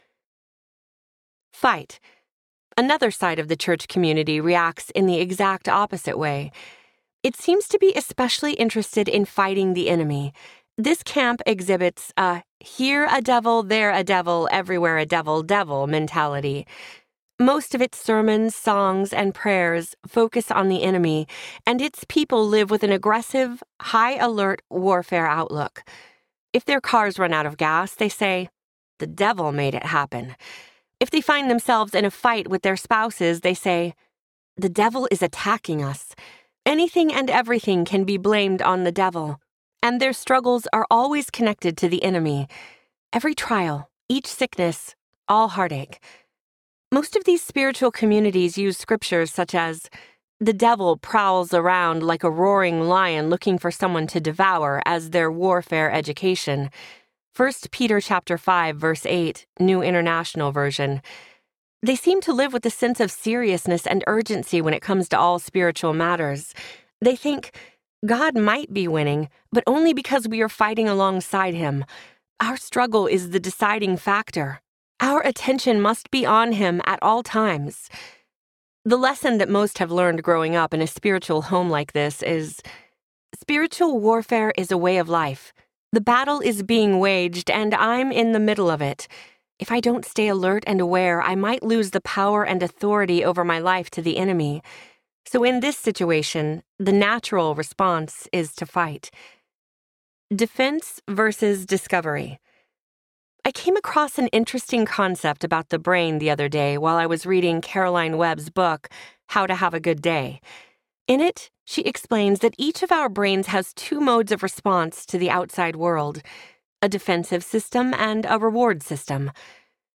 Stronger Than the Struggle Audiobook
Narrator
5.32 Hrs. – Unabridged